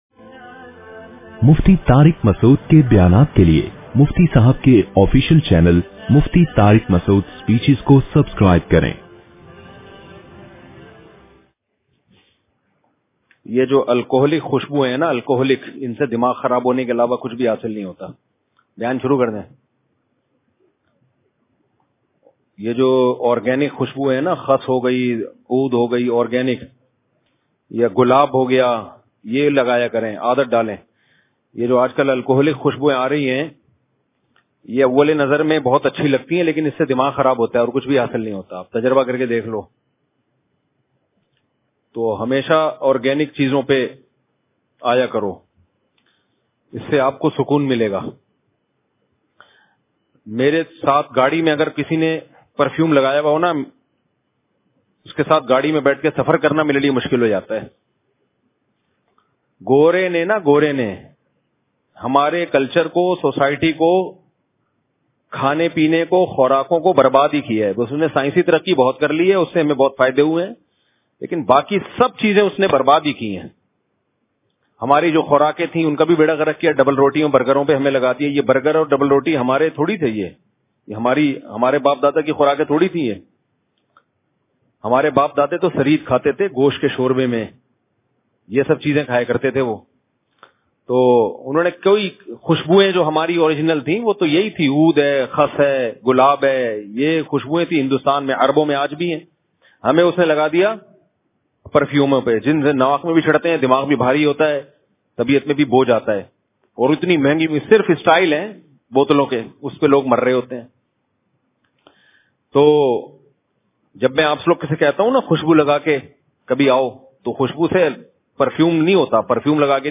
Audio Bayan